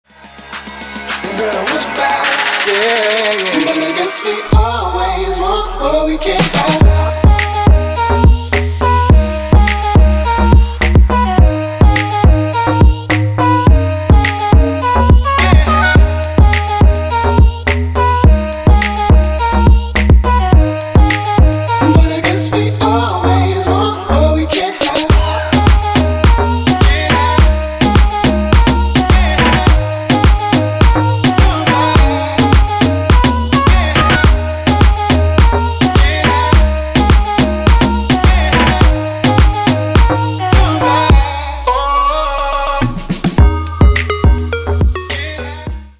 chillout
клубнячок
Indie